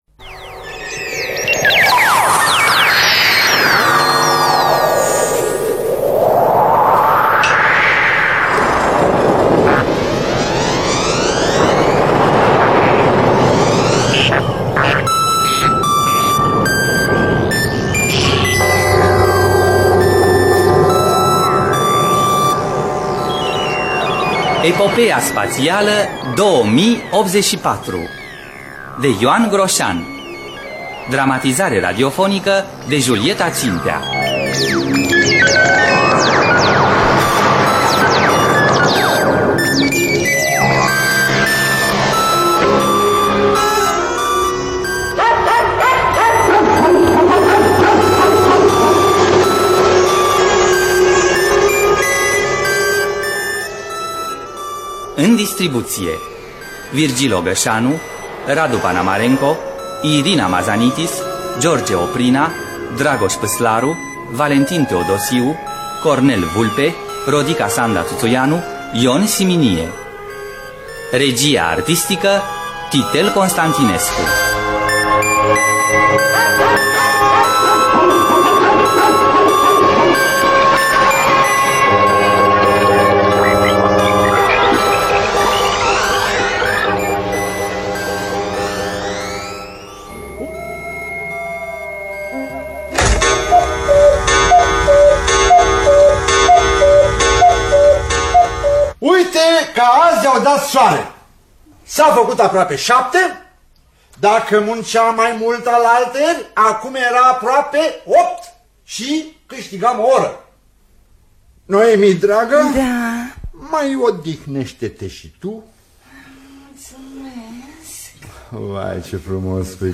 Dramatizarea radiofonică de Julieta Țintea.